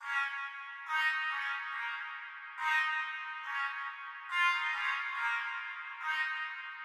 Tag: 140 bpm LoFi Loops Brass Loops 1.15 MB wav Key : A